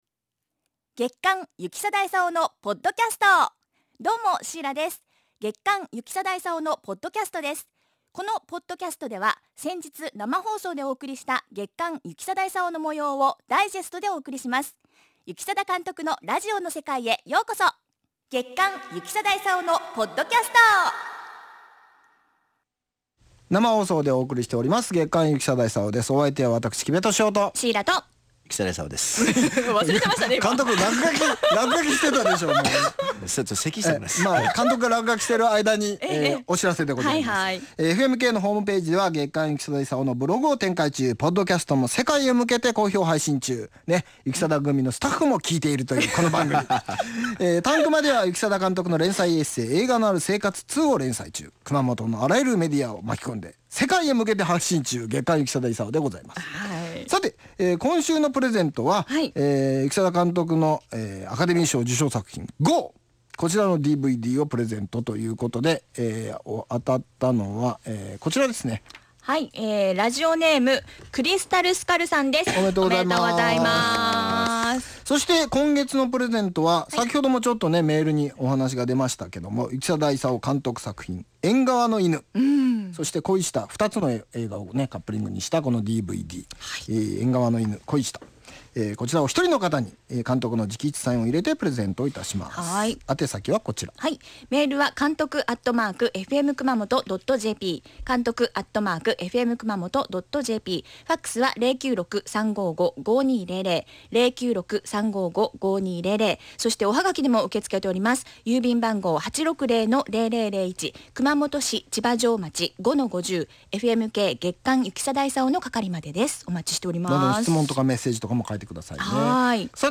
FMラジオ局・エフエム熊本のポッドキャスト。